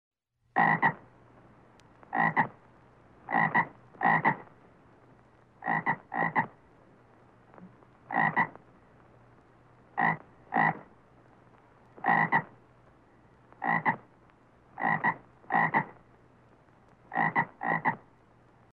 Звуки кваканья лягушки, жабы
Звук кваканья жабы